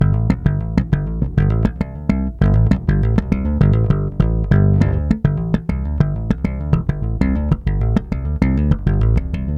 SONS ET LOOPS GRATUITS DE BASSES DANCE MUSIC 100bpm
Basse dance 7